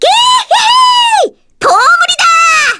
Miruru-Vox_Victory_kr.wav